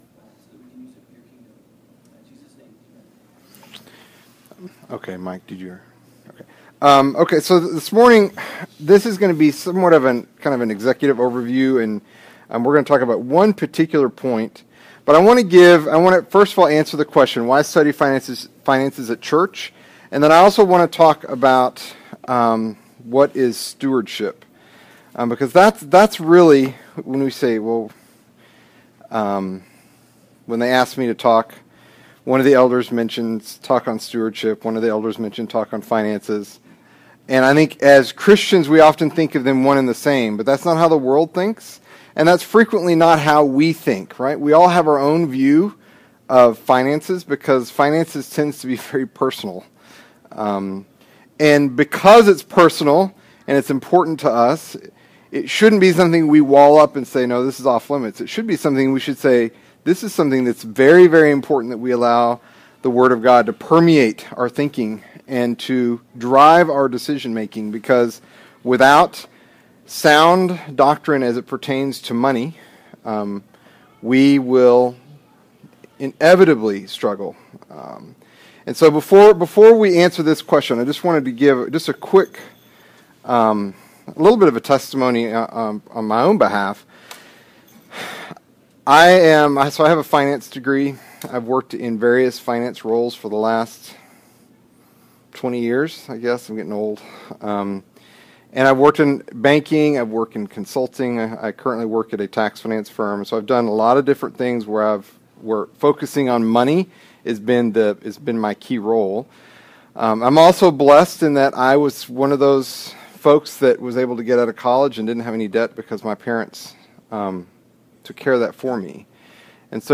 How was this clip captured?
October 2, 2016 (Sunday School)